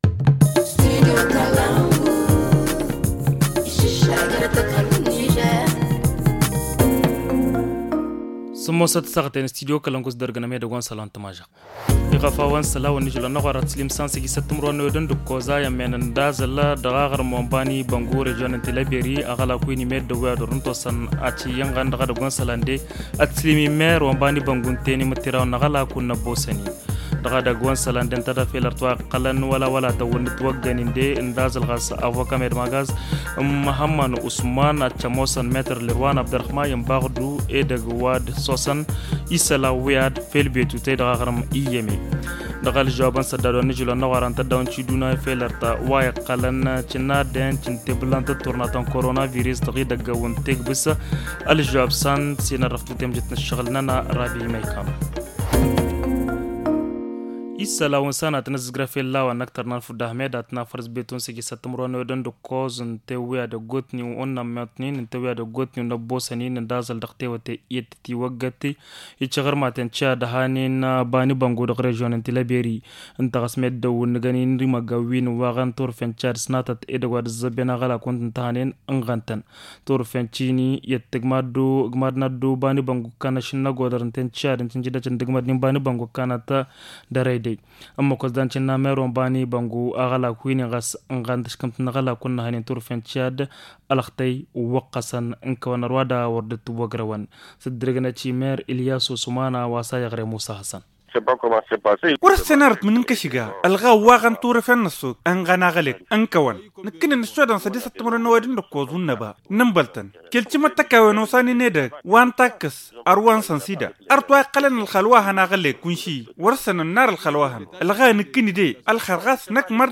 Le journal du 17 mars 2021 - Studio Kalangou - Au rythme du Niger